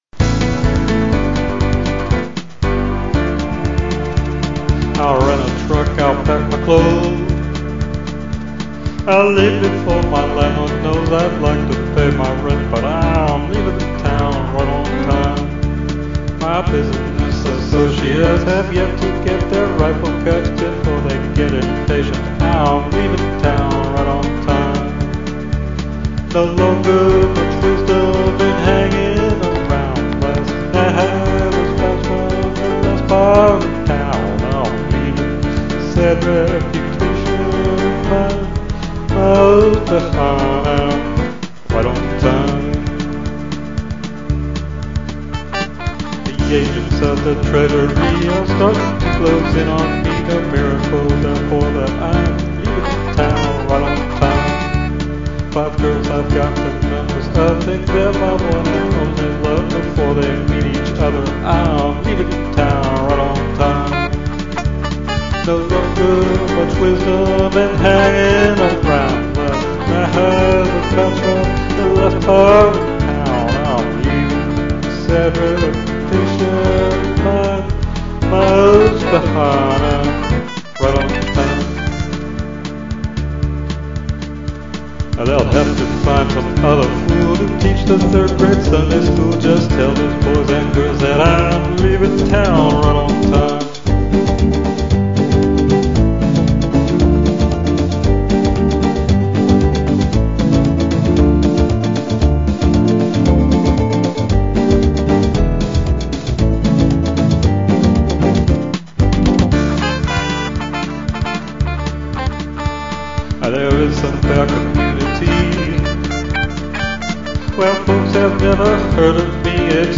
fast 2/2 male or female voice